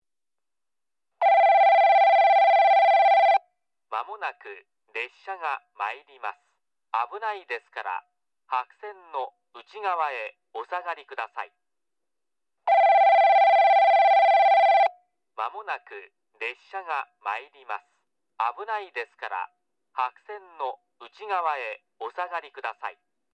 ホームは時間が止まったかのように静まり返っており、鳥の声と付近を走る車の音が小さく聞こえるだけです。
1番のりば接近放送　男声 現在の放送は九州カンノ型Cとなっています。音量も大きくなっています。
スピーカーはＴＯＡラッパ（白）になり、設置位置も若干変わっています。